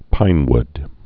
(pīnwd)